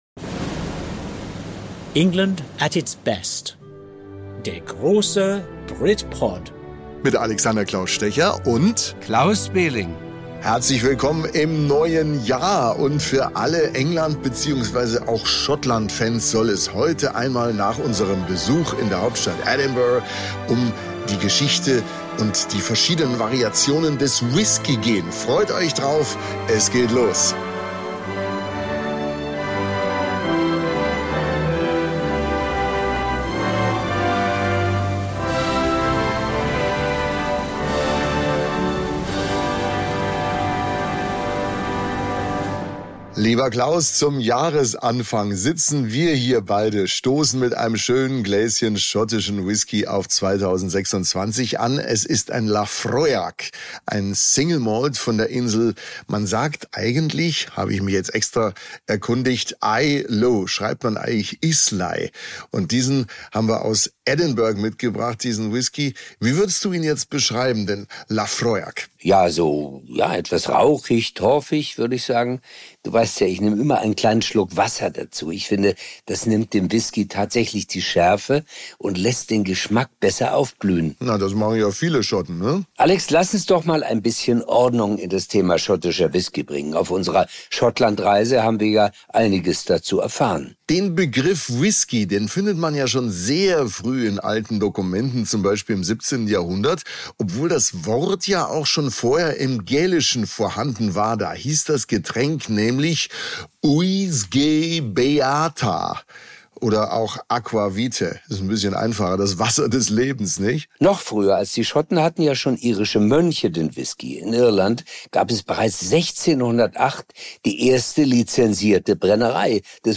Es wird auch historisch - in Edinburgh Castle: Dort erleben die beiden eine überraschende, exklusive Whisky-Verkostung im historischen Ambiente und sprechen mit einem Whisky-Experten über Liköre wie Drambuie, Fasswahl, Mythen rund um Wasserquellen und die Unterschiede zwischen schottischem, irischem und amerikanischem Whisky.